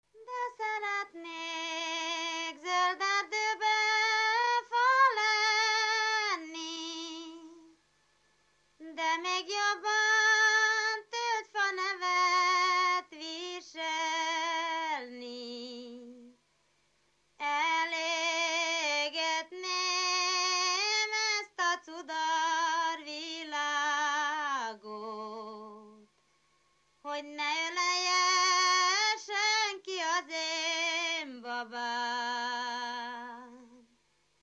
Erdély - Szolnok-Doboka vm. - Buza
ének
Stílus: 6. Duda-kanász mulattató stílus
Kadencia: 4 (1) VII 1